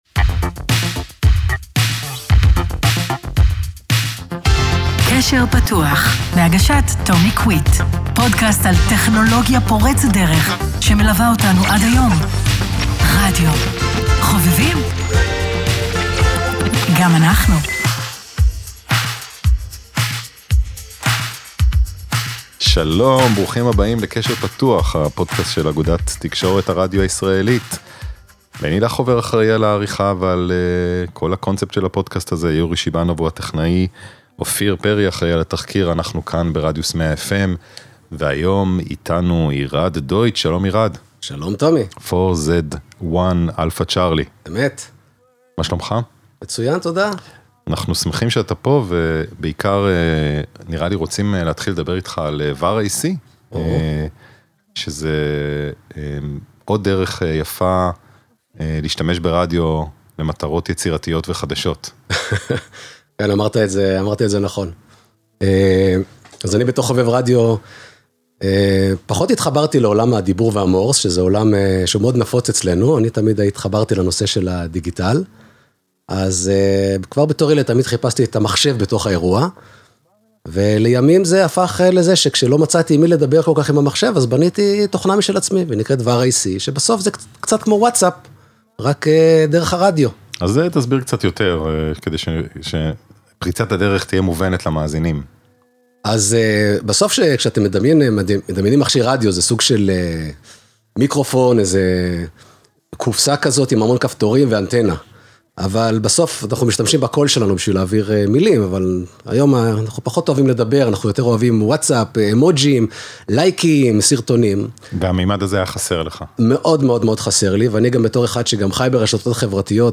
הוקלט באולפני רדיוס